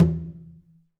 Conga-HitN_v3_rr2_Sum.wav